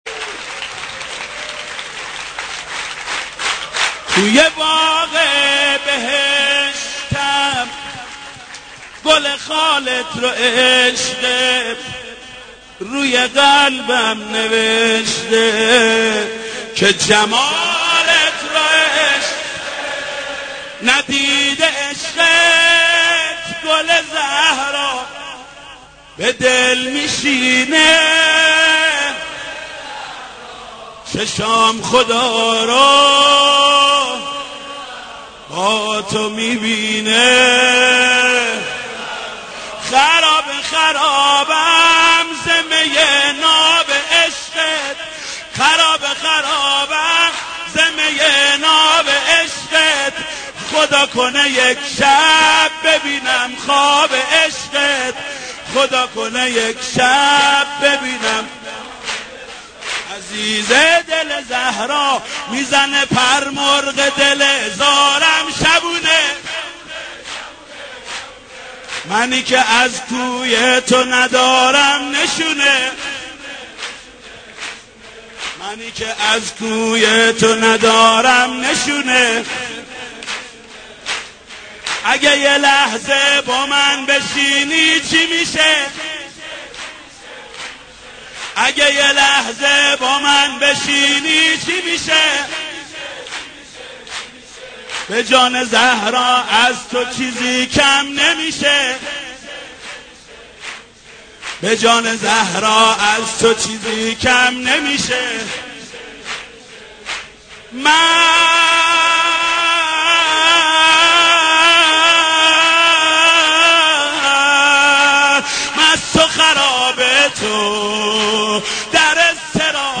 مولودی شاد